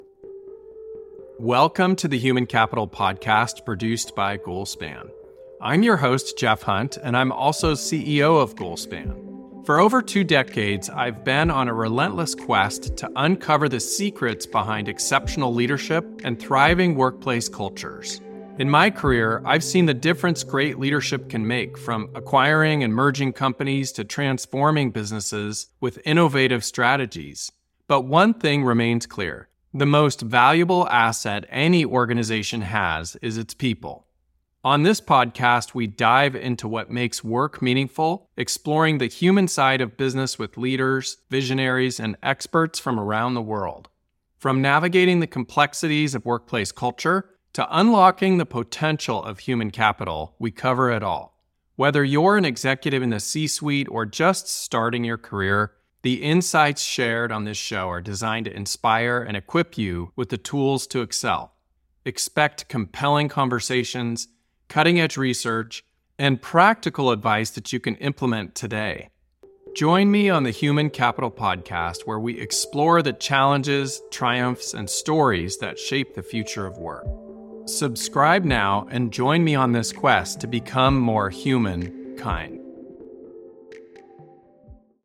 as he interviews guests who help us learn to embrace the value of human capital in new ways.